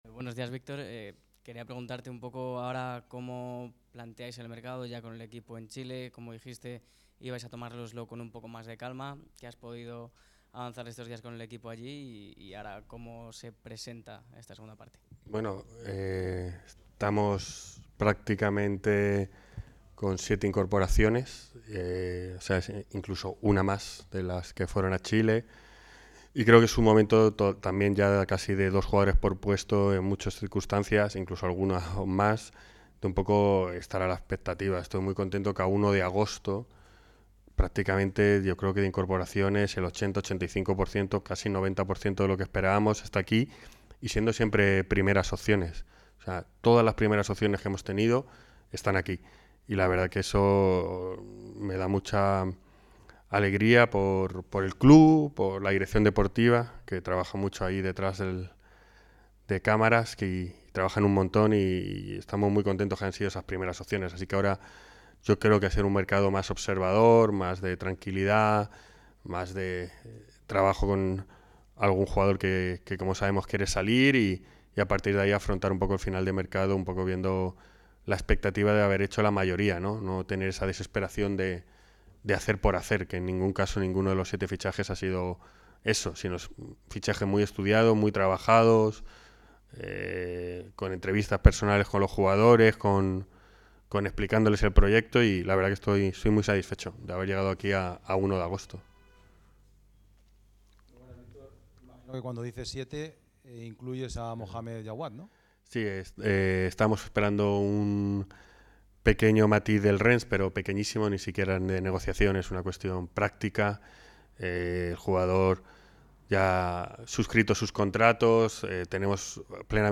Ahora es el momento de devolver con esfuerzo, rendimiento y ganando partidos, no queda otra" sentenció el directivo en un alegato humilde de agradecimiento y compromiso, en una rueda de prensa en la que, adelantó